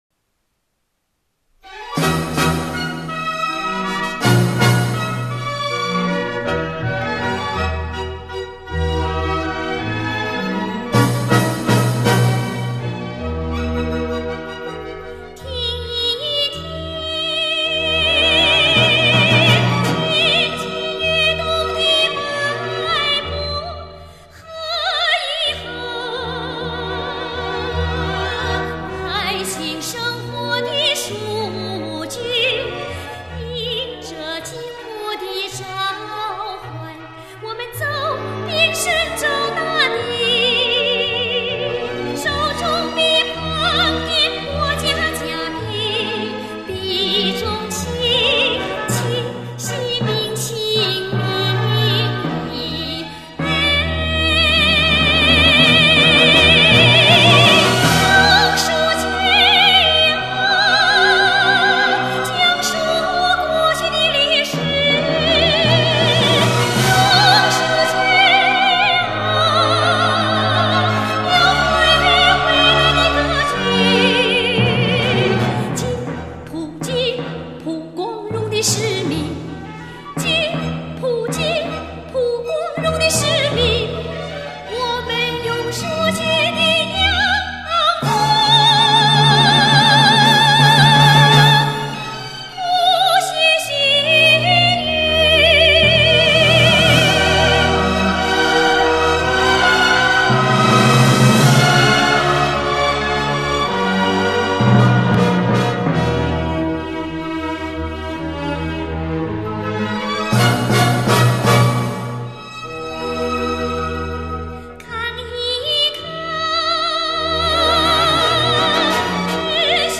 演唱